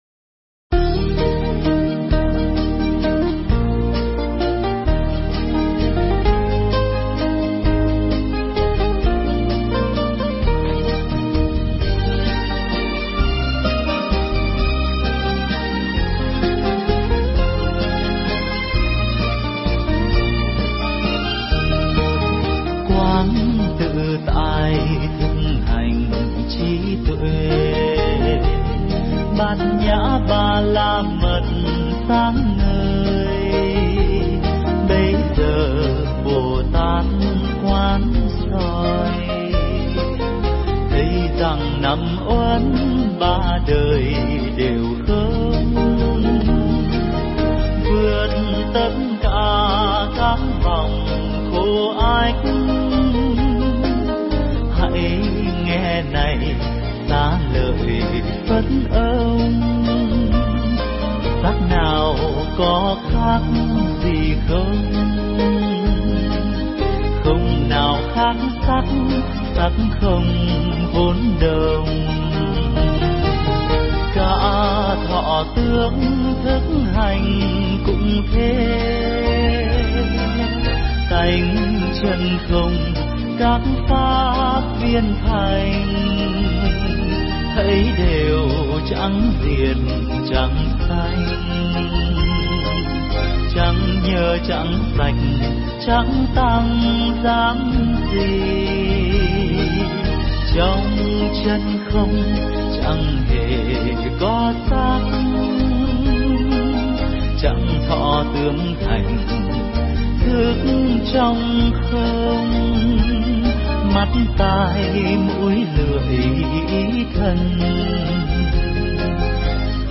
Nghe Mp3 thuyết pháp Niệm Thân Hành Phần 1
Tải mp3 pháp thoại Niệm Thân Hành Phần 1